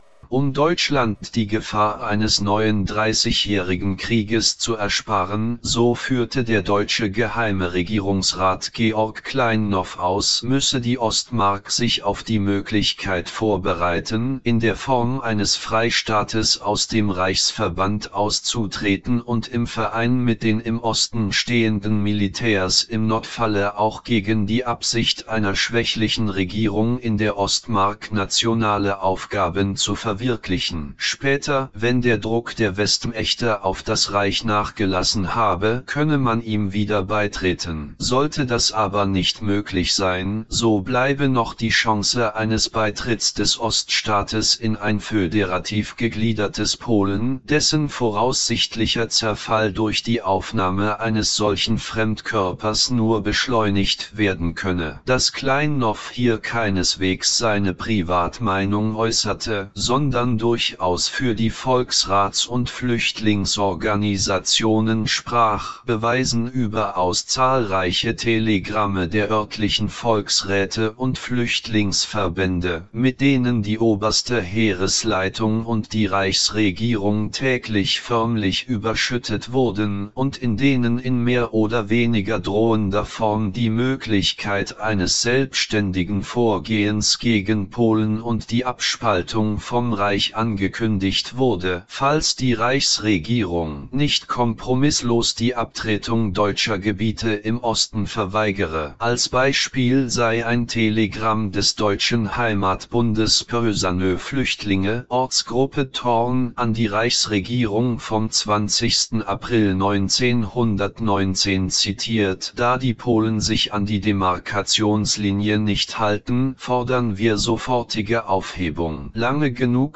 Der vertonte Text hier stammt nur aus diesem interessanten Beitrag aus dem Link: Der Oststaatplan 1919